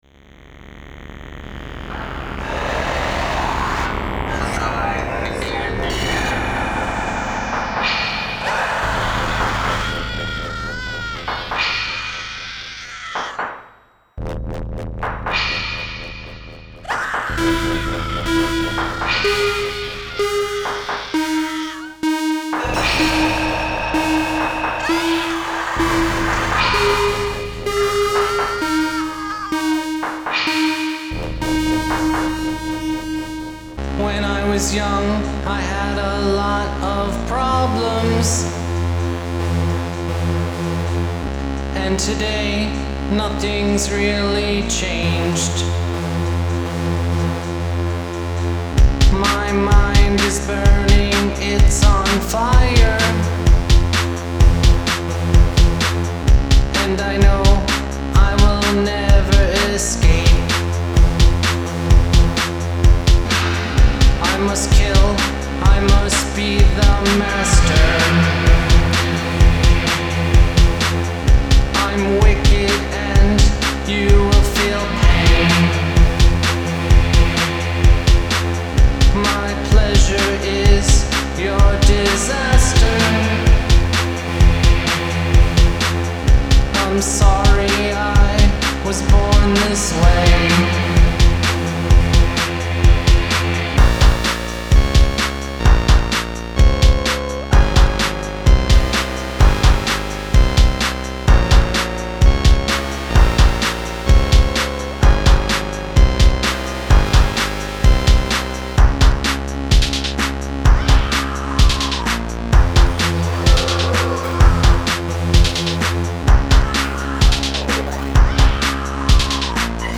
dark electro
morceaux Electro Techon sombres et poisseux